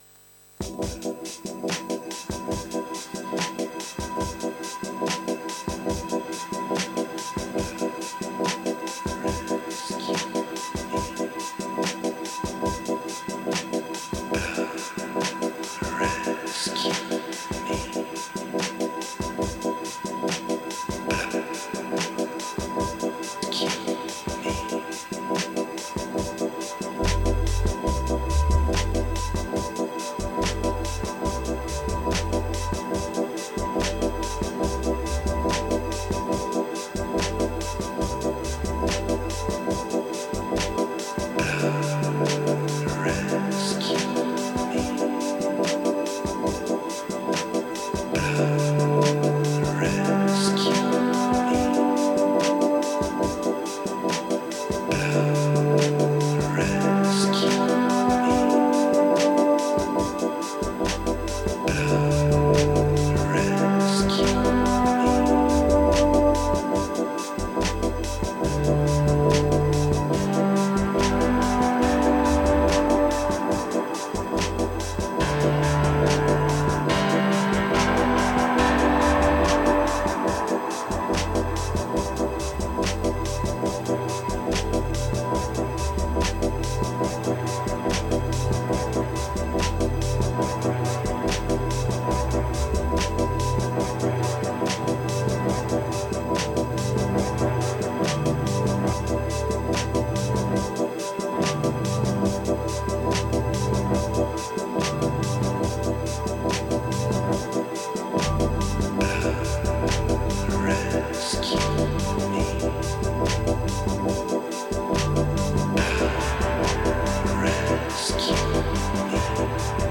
Un diamnche matin, première prise après 1h de travail.